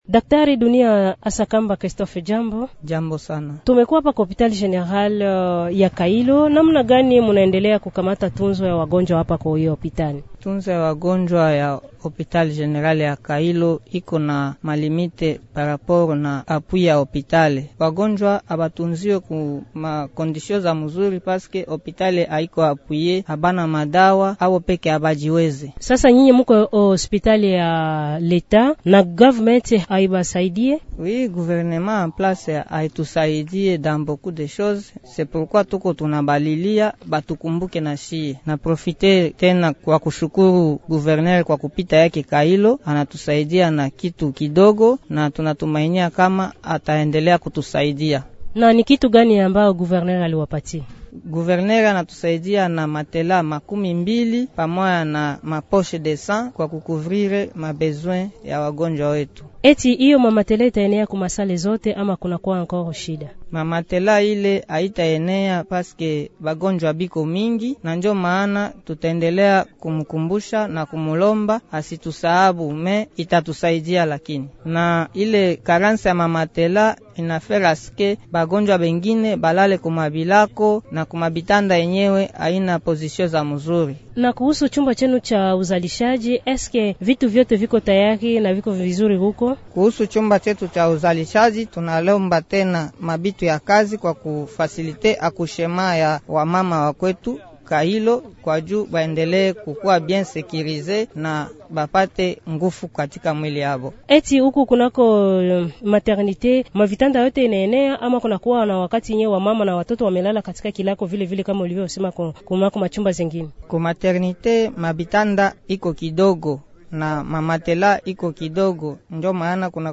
Pamoja na kutokuwa na jengo zuri, hospitali hiyo haina vifaa na haina dawa za kuwahudumia wagonjwa. Daktari wake msimamizi anatoa sauti ya kilio.